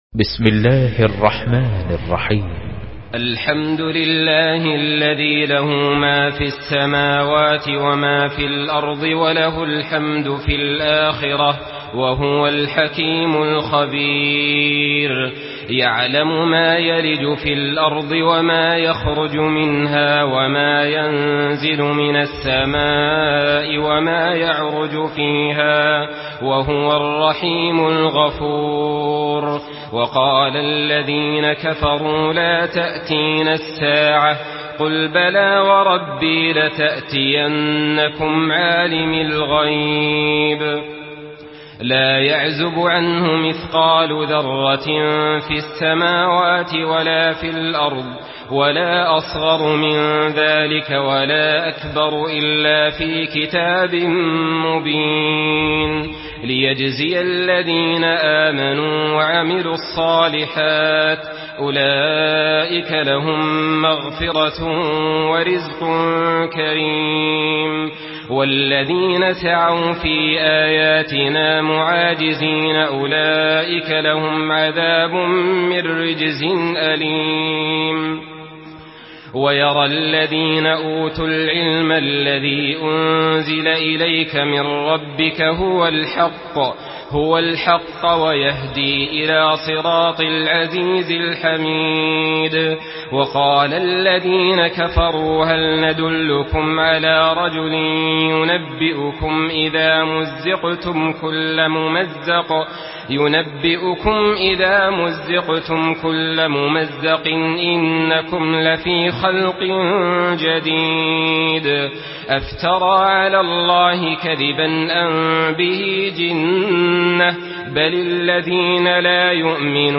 Surah Sebe MP3 by Saleh Al-Talib in Hafs An Asim narration.
Murattal Hafs An Asim